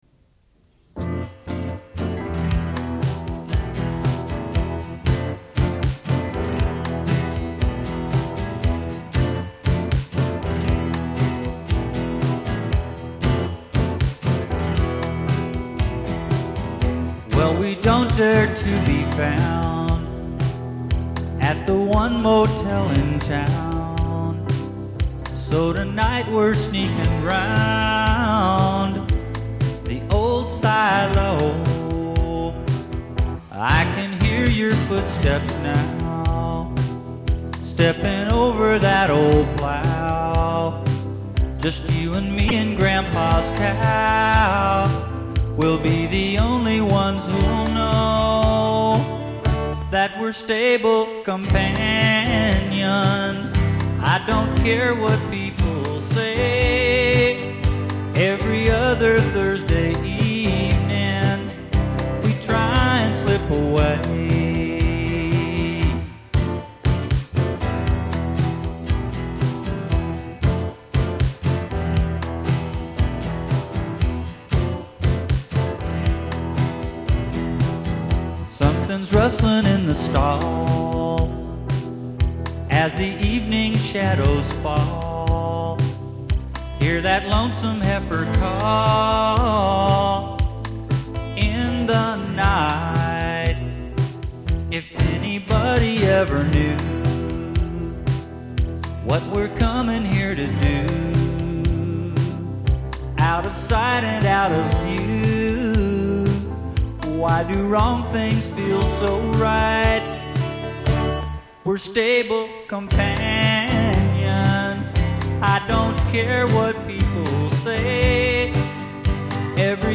4 TRACK DEMO